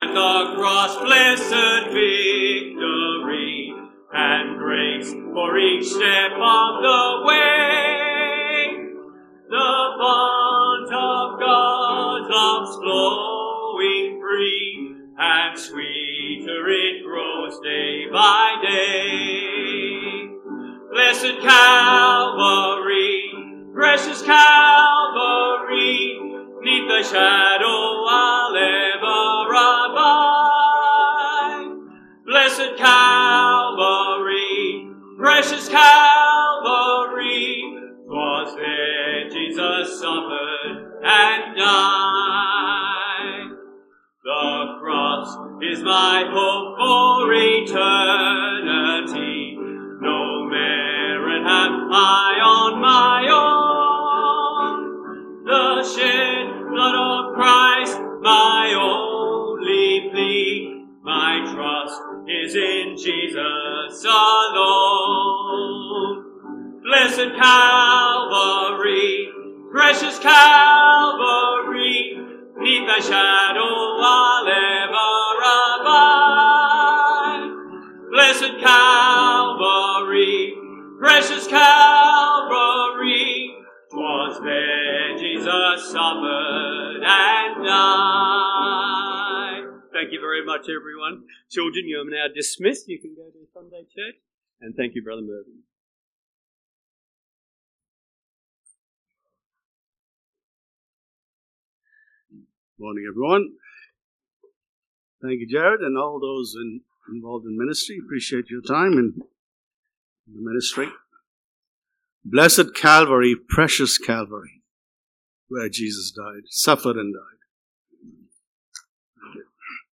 Passage: 1 John 2:28-3:3 Service Type: Sunday AM